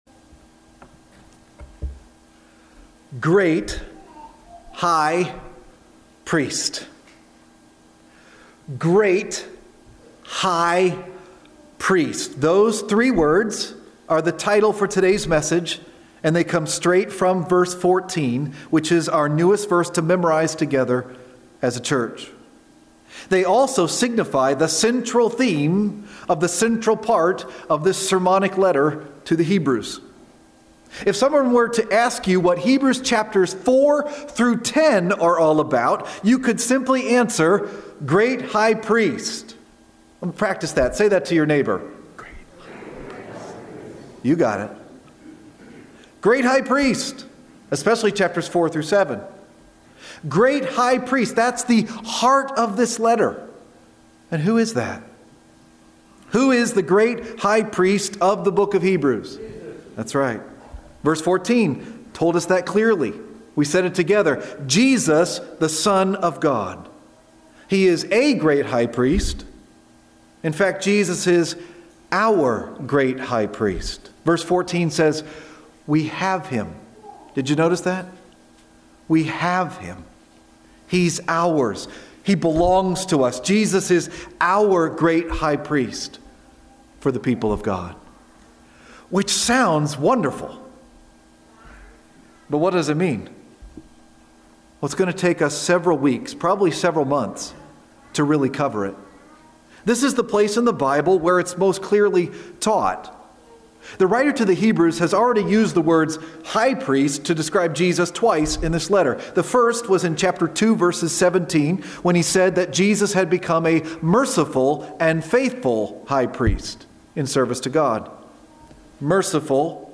preaching on Hebrews 4:14-16